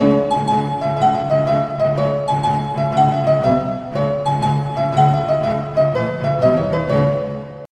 сказочные
ирландские
таверна
Прикольный рингтон смс